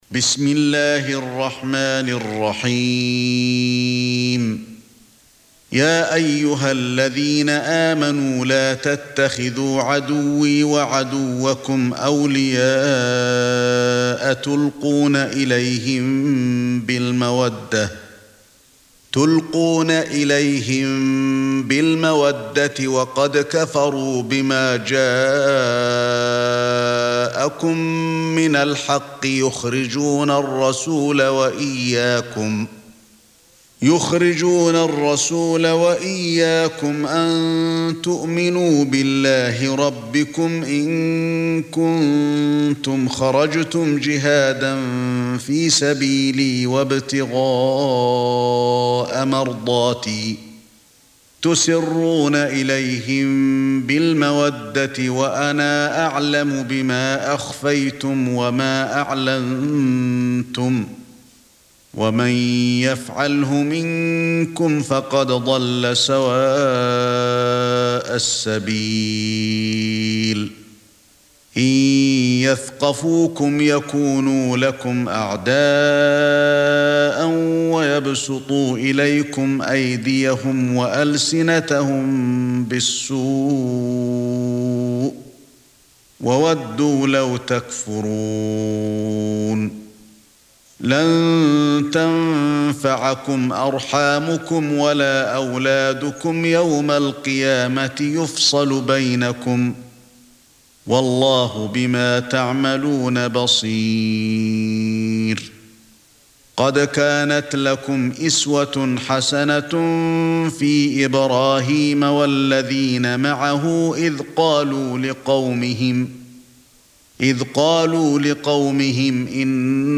60. Surah Al-Mumtahinah سورة الممتحنة Audio Quran Tarteel Recitation
Surah Sequence تتابع السورة Download Surah حمّل السورة Reciting Murattalah Audio for 60.